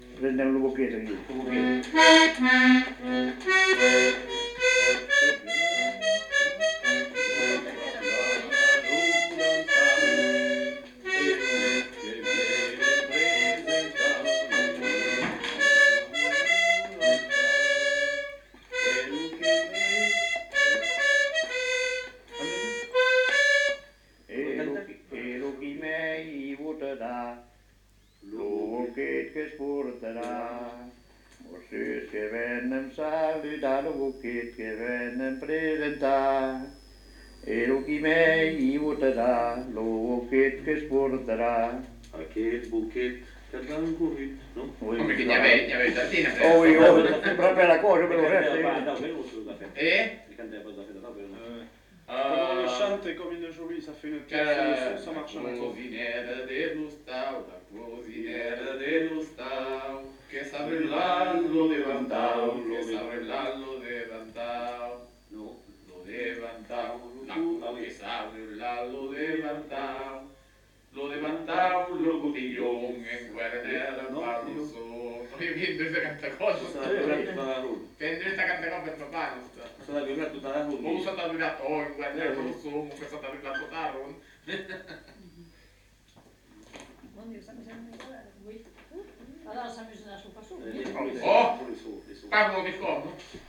Aire culturelle : Petites-Landes
Lieu : Lencouacq
Genre : chanson-musique
Effectif : 1
Type de voix : voix d'homme
Production du son : chanté
Instrument de musique : accordéon diatonique